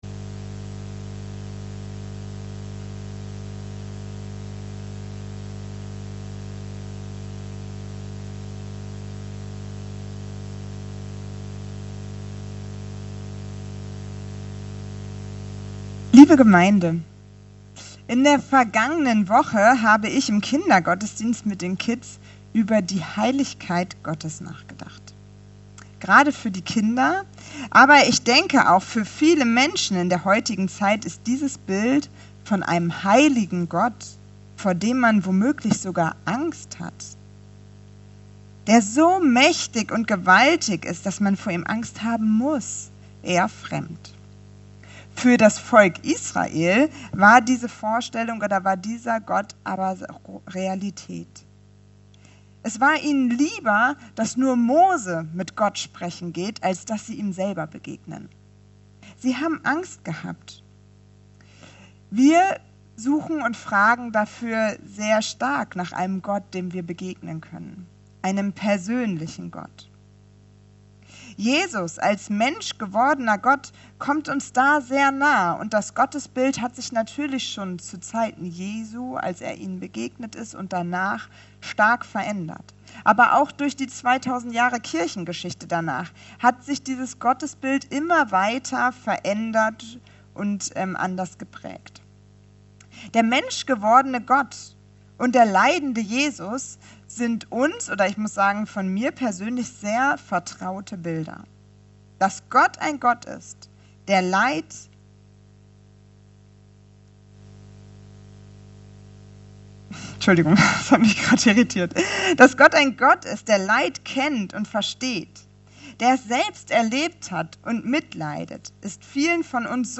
Evangelisch-Freikirchliche Gemeinde Kelkheim - Predigten anhören